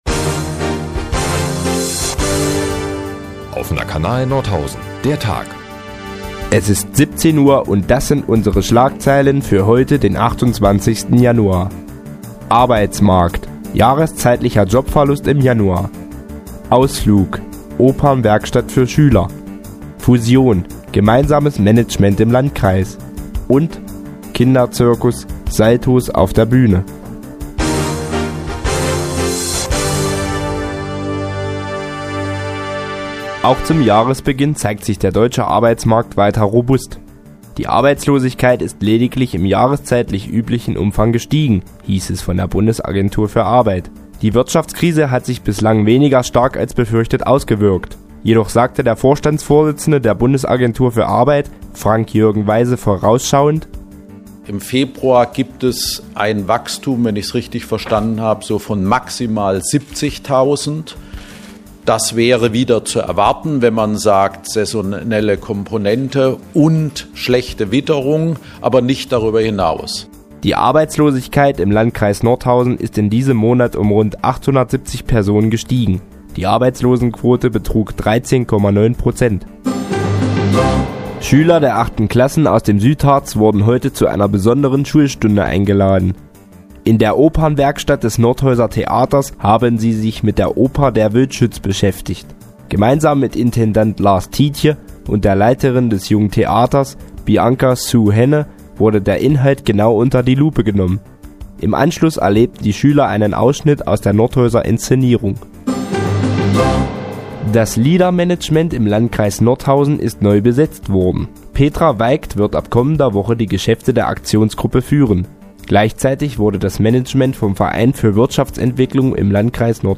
Die tägliche Nachrichtensendung des OKN ist nun auch in der nnz zu hören. Heute geht es um die neuen Arbeitslosenzahlen und die Programmvorbbereitungen des Zirkus "Zappelini".